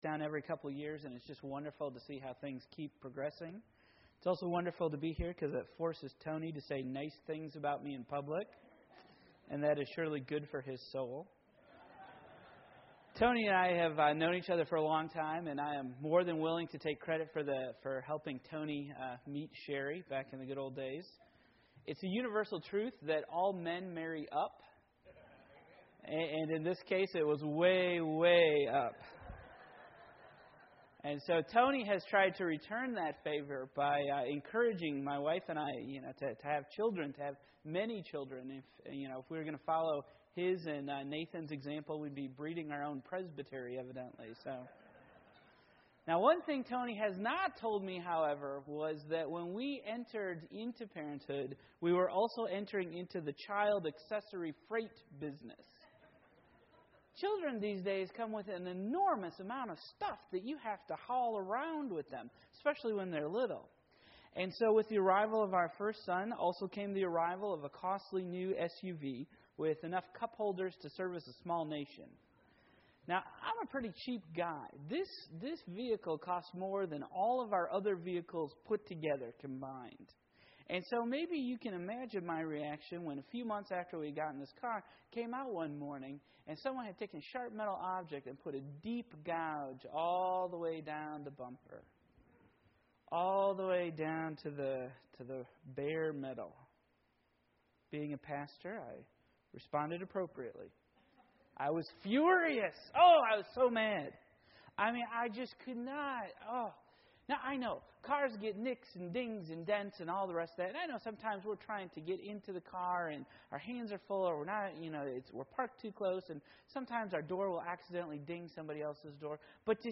Matthew 18:21-25 Service Type: Morning Worship A Tenderhearted King